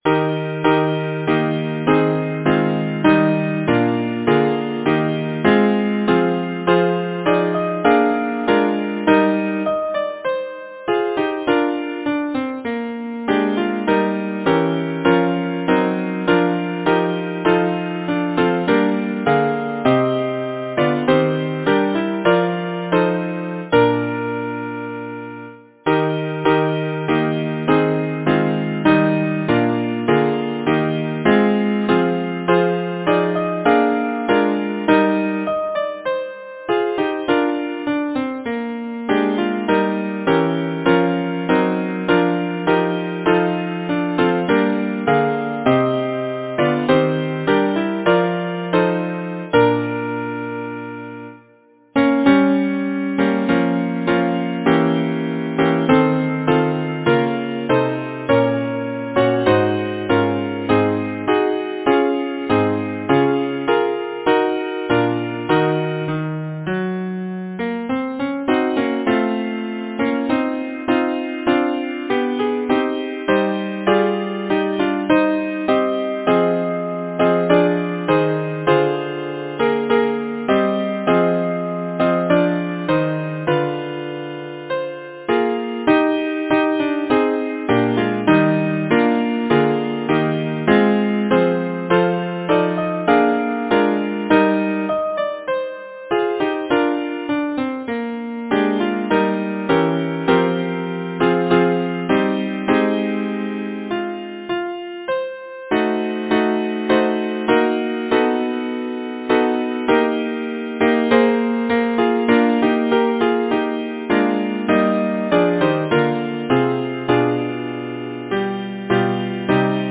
Title: Beside a placid silver stream Composer: Claudius H. Couldery Lyricist: Mrs. E. N. Seagercreate page Number of voices: 4vv Voicing: SATB Genre: Secular, Partsong
Language: English Instruments: A cappella